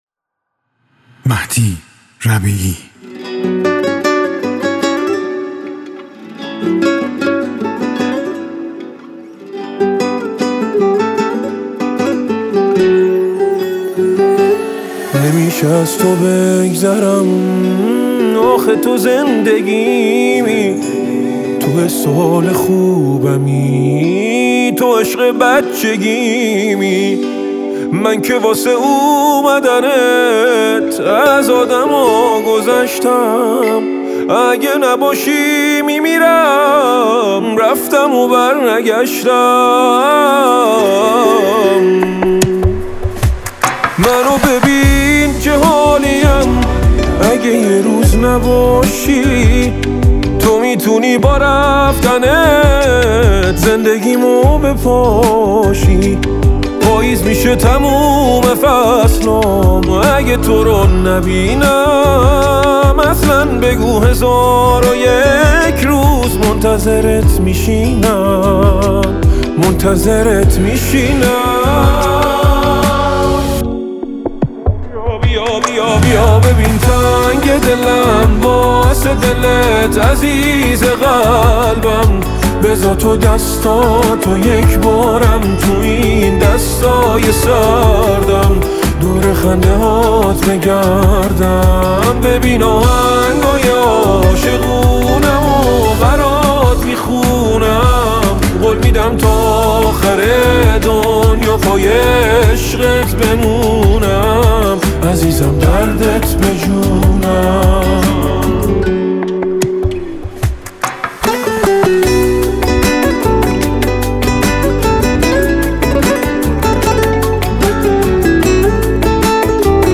گیتار